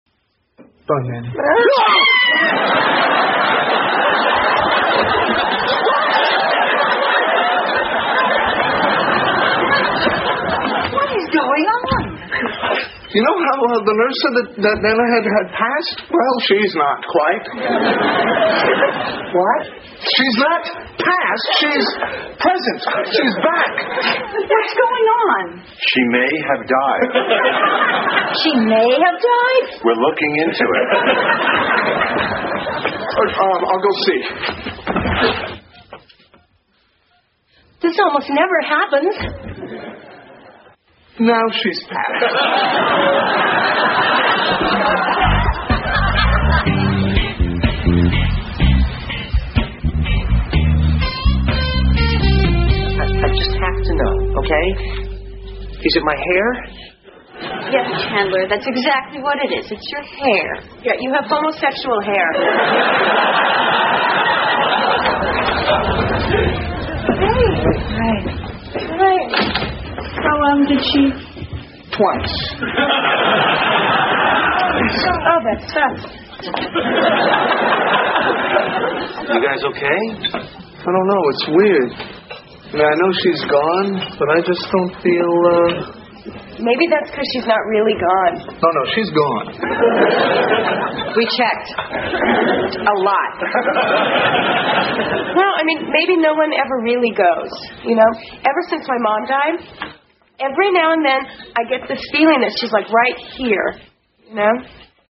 在线英语听力室老友记精校版第1季 第90期:祖母死了两回(4)的听力文件下载, 《老友记精校版》是美国乃至全世界最受欢迎的情景喜剧，一共拍摄了10季，以其幽默的对白和与现实生活的贴近吸引了无数的观众，精校版栏目搭配高音质音频与同步双语字幕，是练习提升英语听力水平，积累英语知识的好帮手。